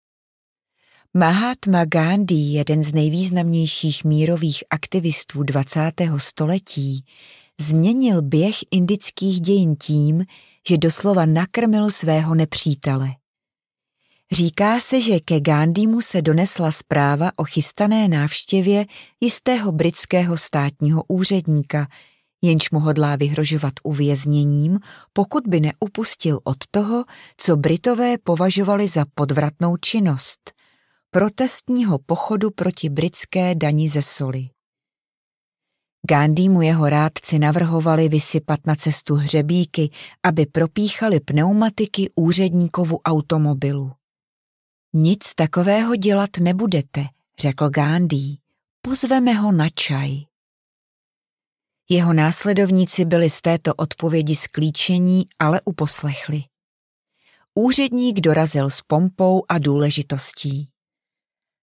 Pečuj o své démony audiokniha
Ukázka z knihy
pecuj-o-sve-demony-audiokniha